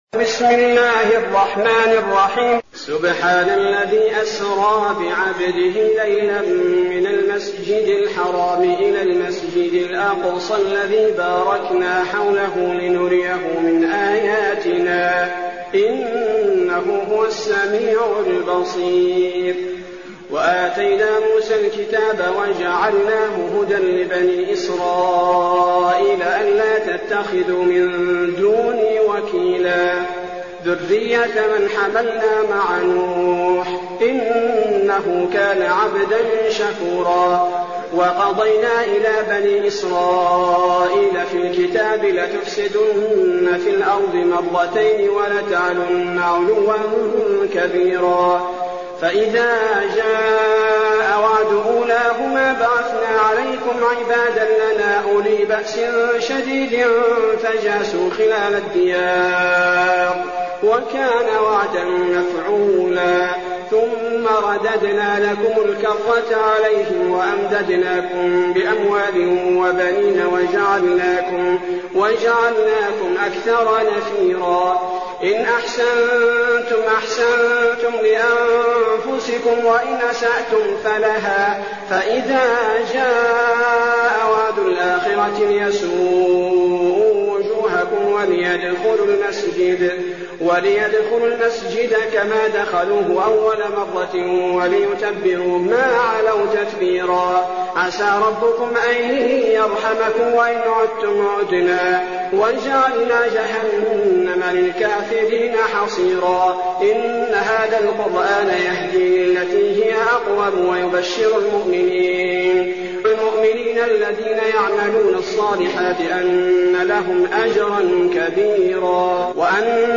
المكان: المسجد النبوي الشيخ: فضيلة الشيخ عبدالباري الثبيتي فضيلة الشيخ عبدالباري الثبيتي الإسراء